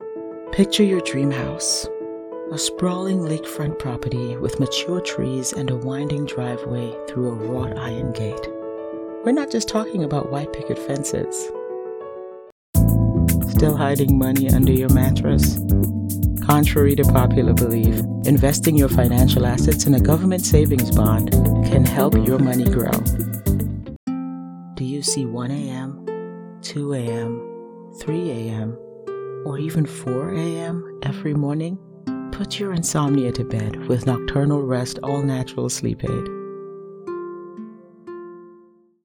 Inglés (Caribe)
Demo comercial
Estudio: Estudio profesional en casa con tratamiento acústico para audio seco de calidad de emisión.
ContraltoProfundoBajo